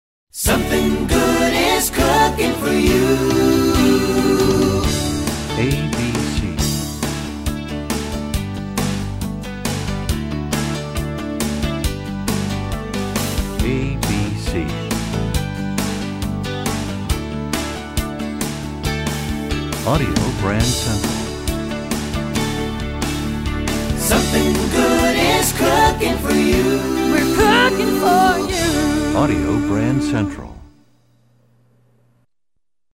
MCM Category: Ad Jingles